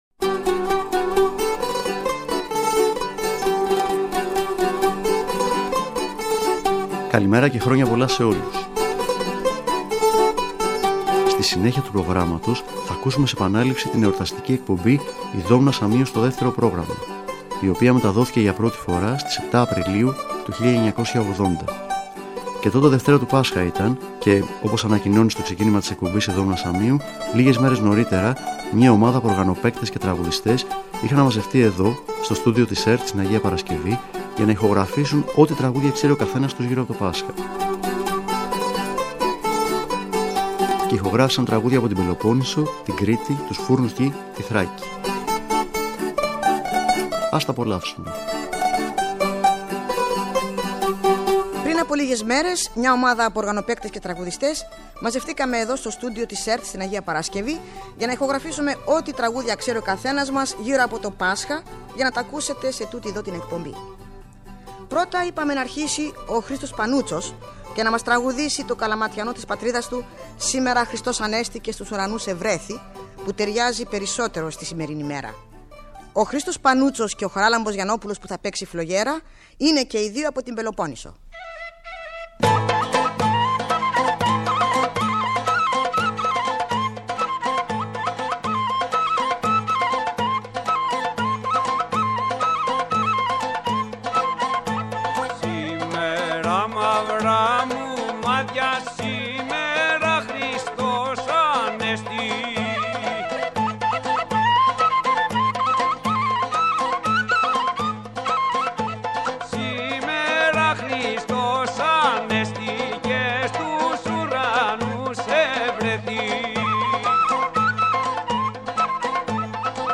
Ένα ακόμα μουσικό ταξίδι με τη Δόμνα Σαμίου στο τιμόνι. Αυτή τη φορά, η σπουδαία ερευνήτρια κι ερμηνεύτρια της μουσικής μας παράδοσης, ζήτησε από τους αγαπημένους της οργανοπαίκτες και τραγουδιστές να ηχογραφήσουν όσα τραγούδια ξέρει ο καθένας τους γύρω από το Πάσχα.